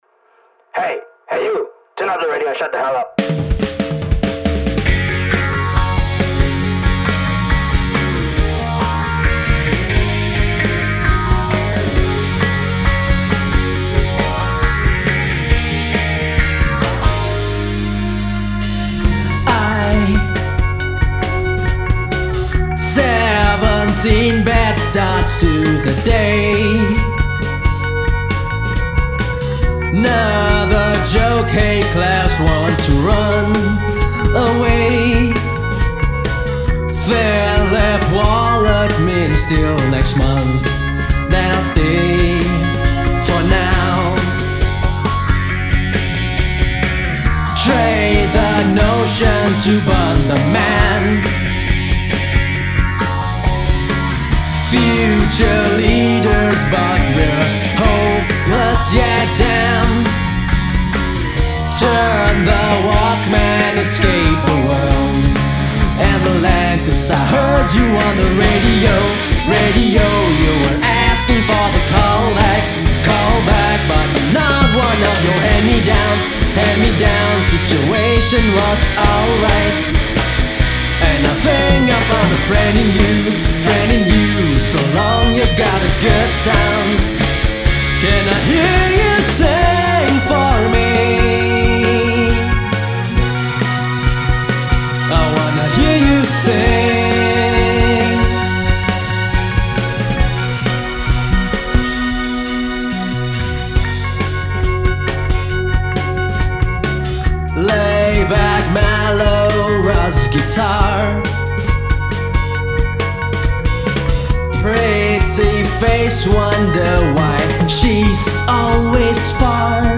Anyway, I used to play guitar in a band, and we called ourselves “Dire Dilemmas”.
The quality is nowhere near what you would get from a professional studio with equipment worth millions of dollars but hey, we are still proud of what we accomplished.